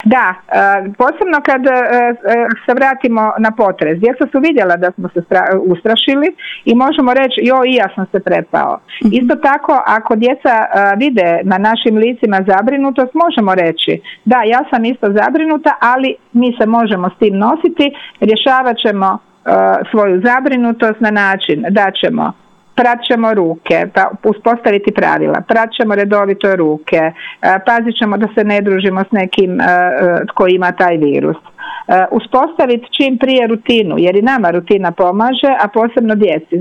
Intervjuu tjedna Media servisa